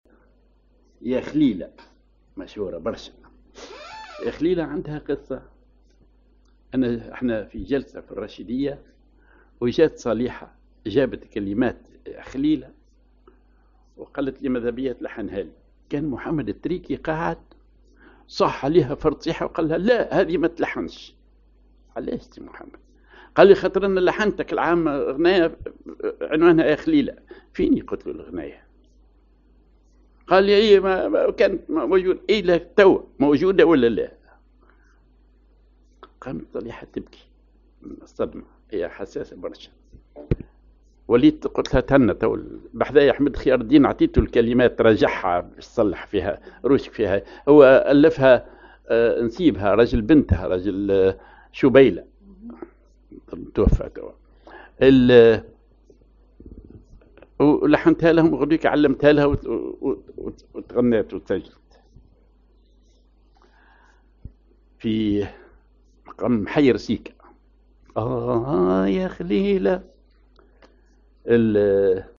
ar المحير سيكاه
ar مدور توني (أو غيطة)
أغنية